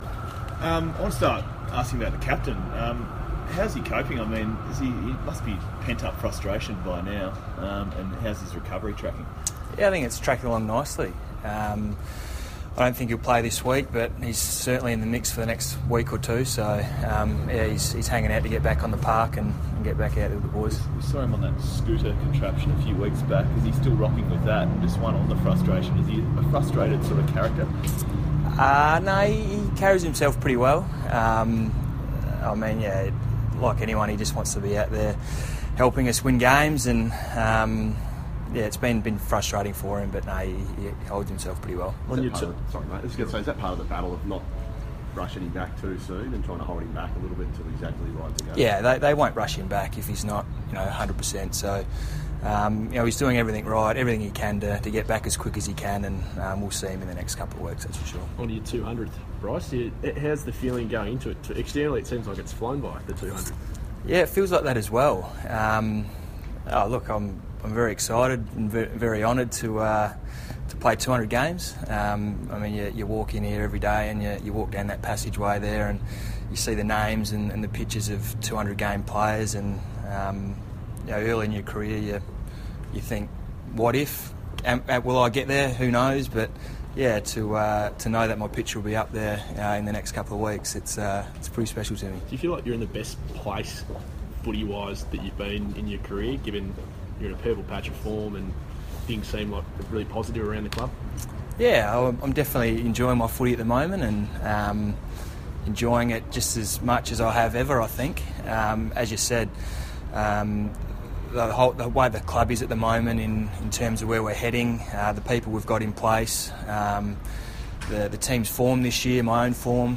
Bryce Gibbs press conference - June 21
Carlton midfielder Bryce Gibbs fronts the media at Ikon Park ahead of his 200th AFL game.